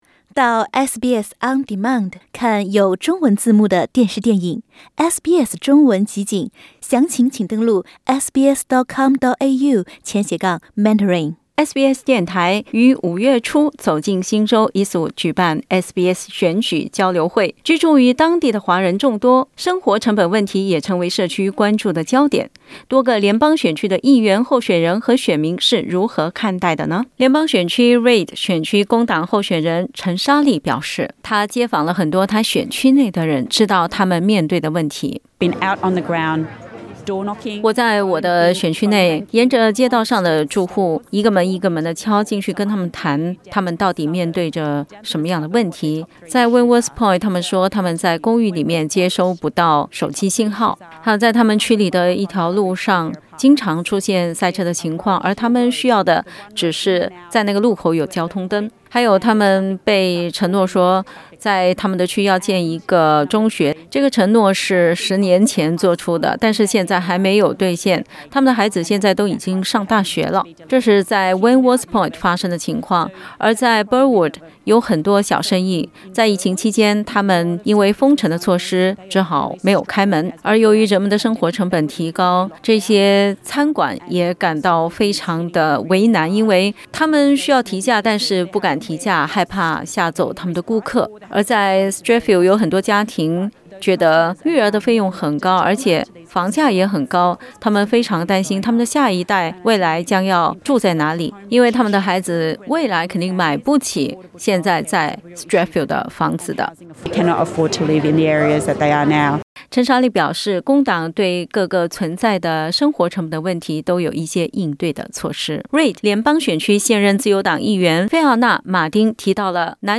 （点击首图收听采访音频）